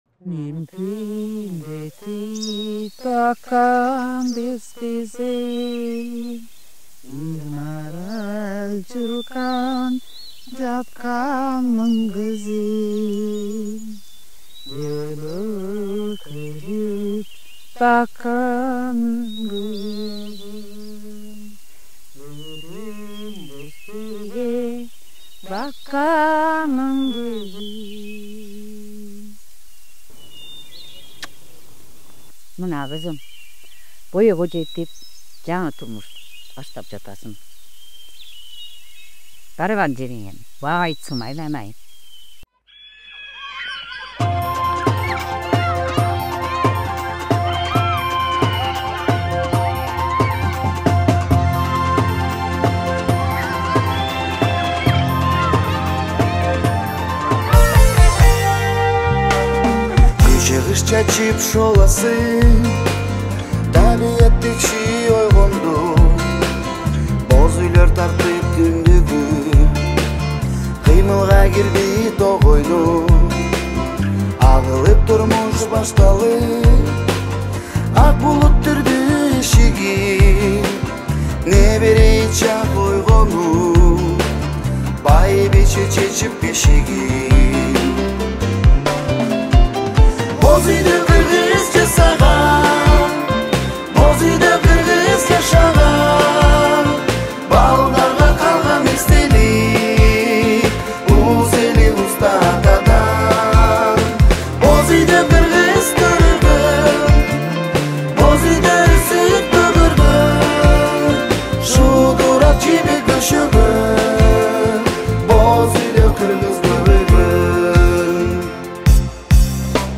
• Категория: Кыргызские песни